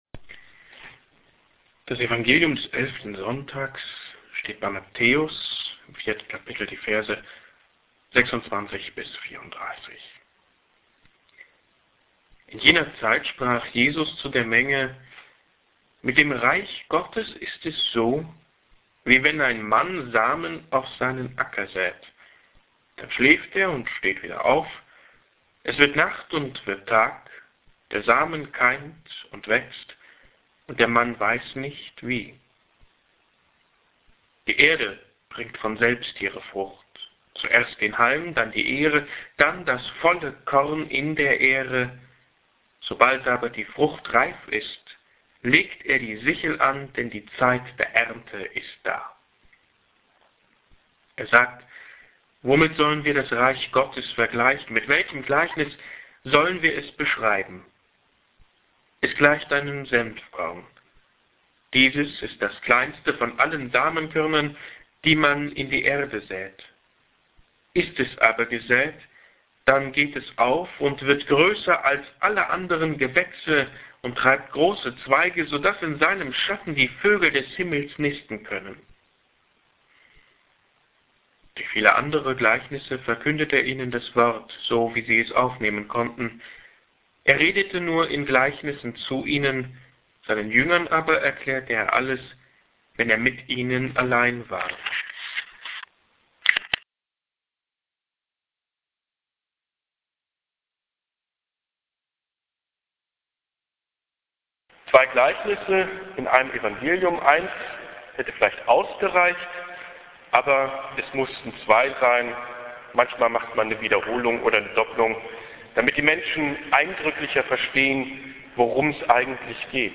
hier-klickt-die-predigt.mp3